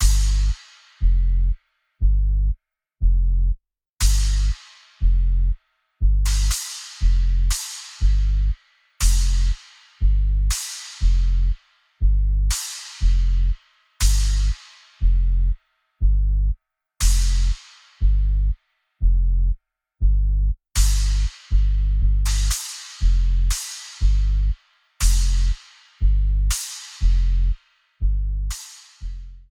Source Ripped from the game